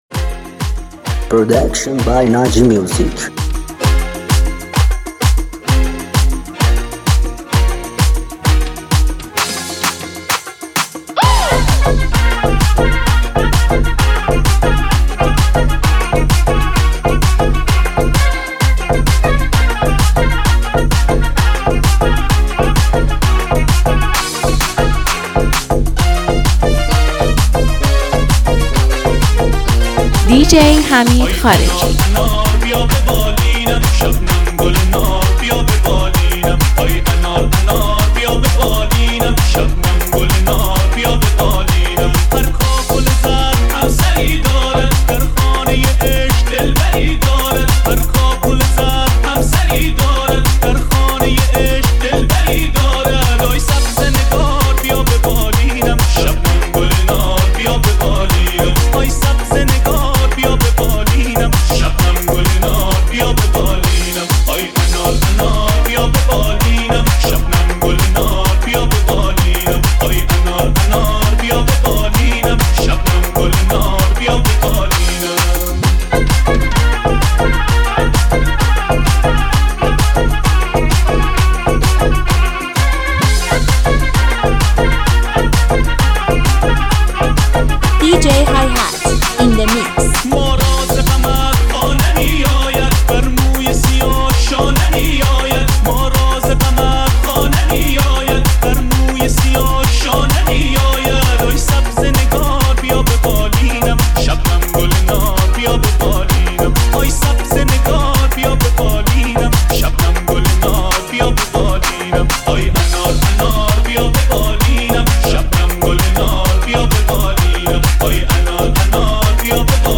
1,264 بازدید ۲۳ آذر ۱۴۰۲ ریمیکس , ریمیکس فارسی